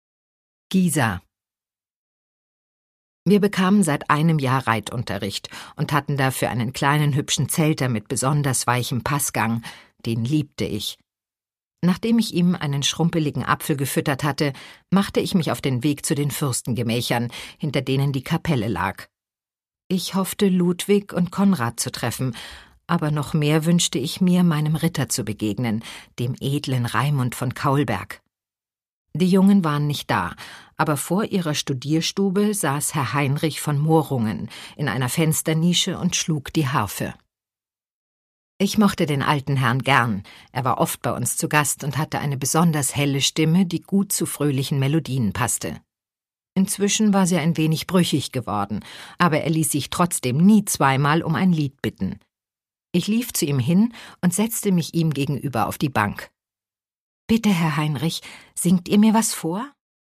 Produkttyp: Hörbuch-Download
Fassung: Autorisierte Lesefassung